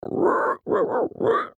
audio: Converted sound effects
AV_pig_long.ogg